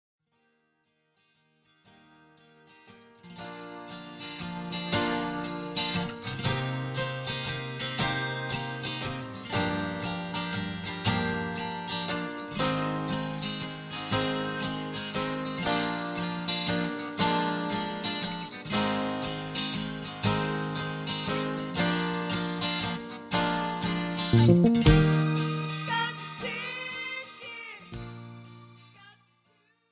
This is an instrumental backing track cover.
Key – D#/Eb
With Backing Vocals
No Fade